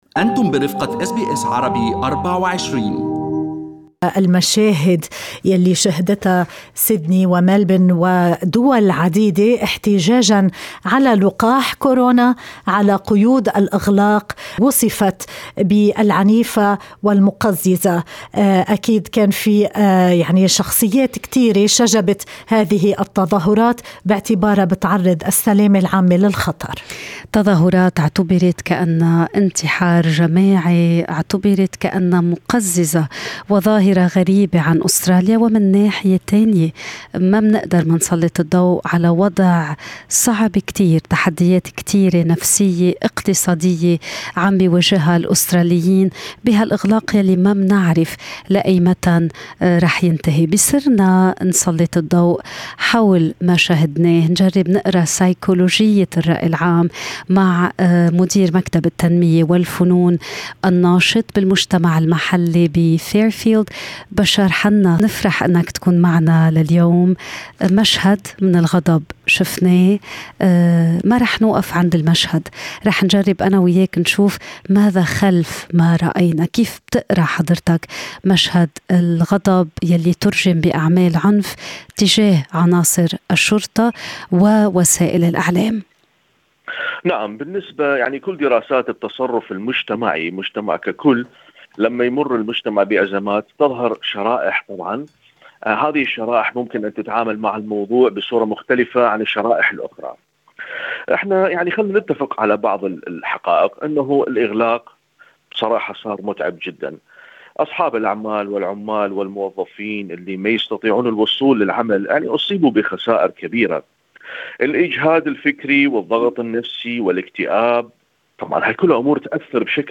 في لقاء مع أس بي أس عربي24